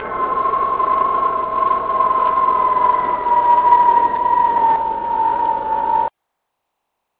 Harmonic turbine sound played as Manta Ray moved away from camera.
This sound is characteristic of synthetic sounds, and is also similar to what musical instruments produce.